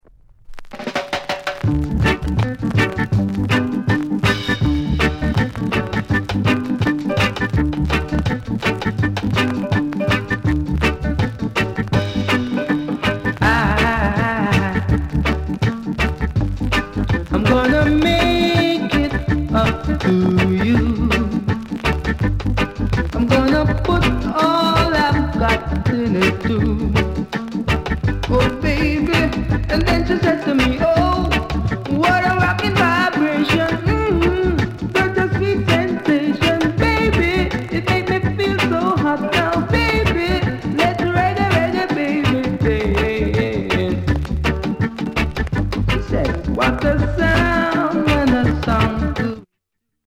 SOUND CONDITION A SIDE VG-(OK)
VERY RARE ROCKSTEADY INST